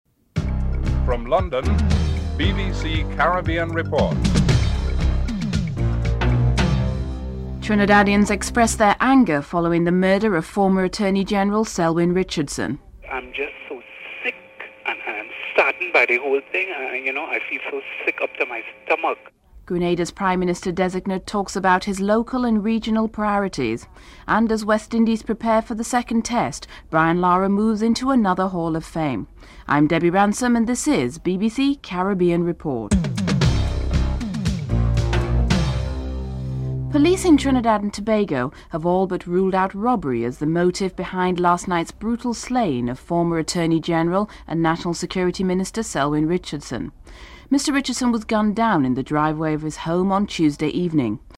Caribbean Report took to the streets to get the people's reaction to the election results. Prime Minister-designate Dr. Keith Mitchell comments on his local and regional priorities.